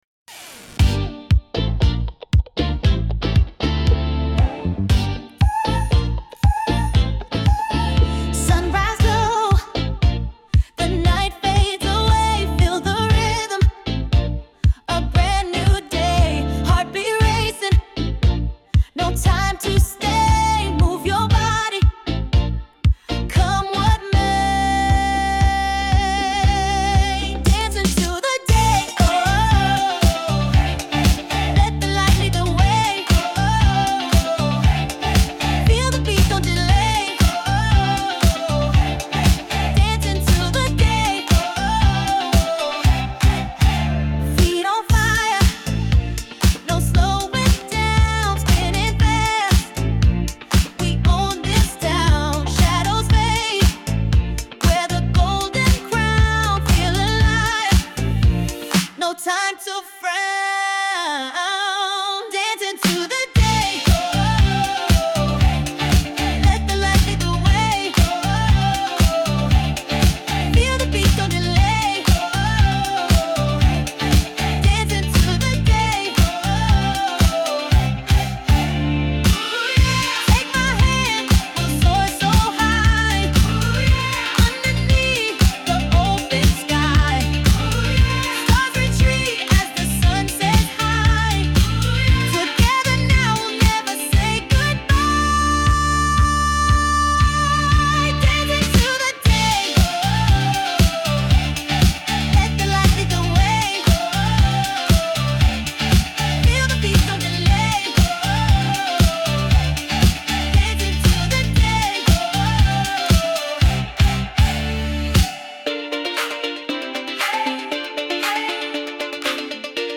Genre:                   R&B/Soul (Dance)